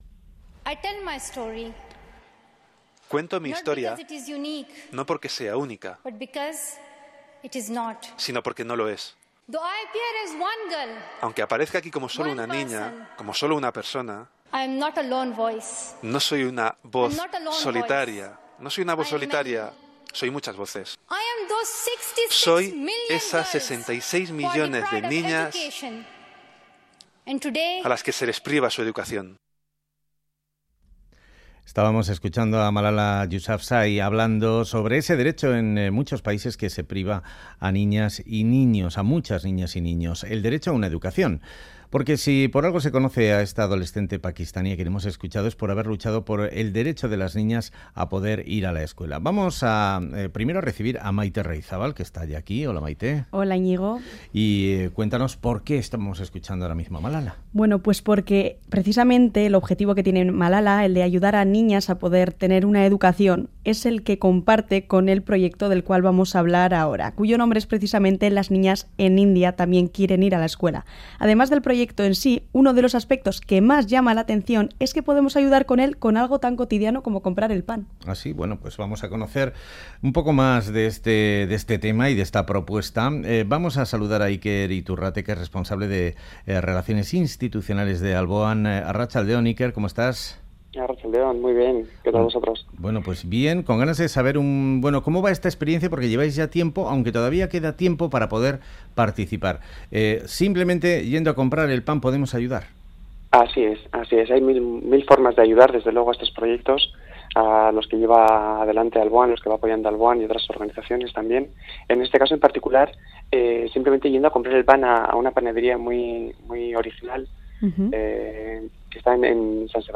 La panadería The Loaf, en Donostia, vende desde el 12 de julio y hasta finales de mes un pan artesanal con productos traídos de la India y con cuya compra se puede colaborar con el proyecto 'Las niñas en India también quieren ir a la escuela', de la ONG Alboan. Entrevistamos